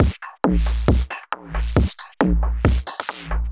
Index of /musicradar/uk-garage-samples/136bpm Lines n Loops/Beats